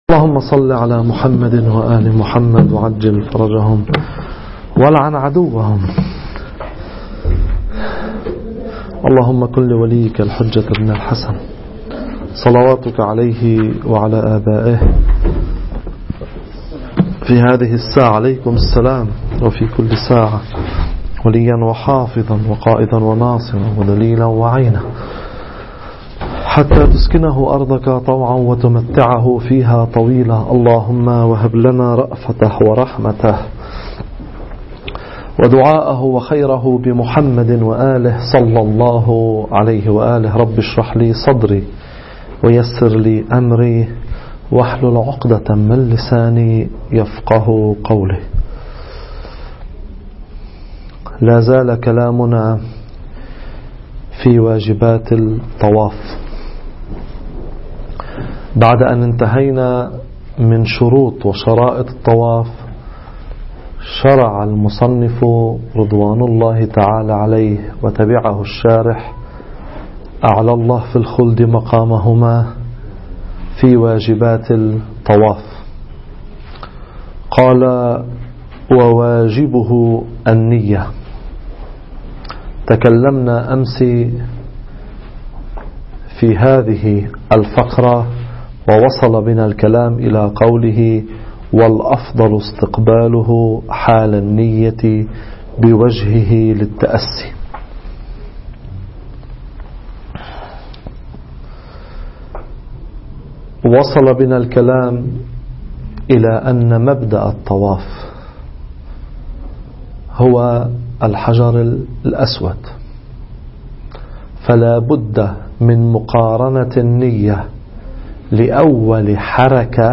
صوت الدرس